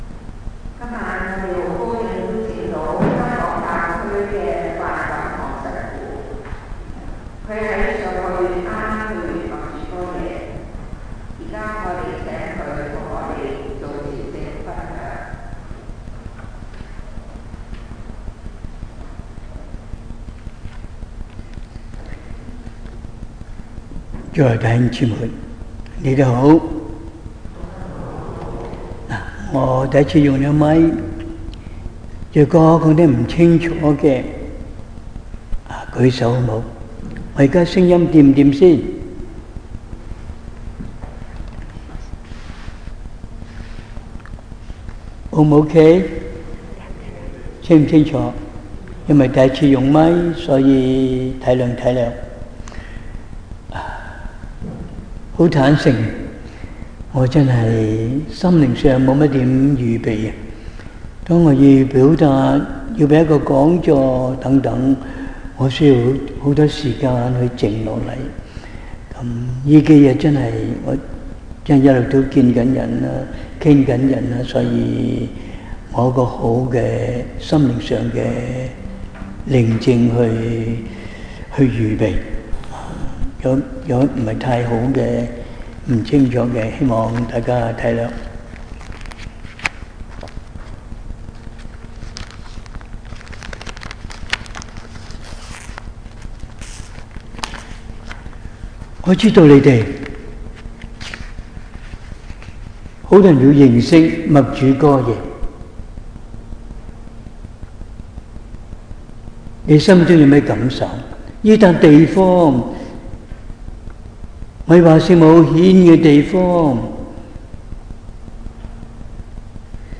Homily
2025年11月14日 – 默主哥耶朝聖分享 (多倫多中華殉道聖人堂) November 14, 2025 – Medjugorje Sharing from Chinese Martyrs Catholic Church in Toronto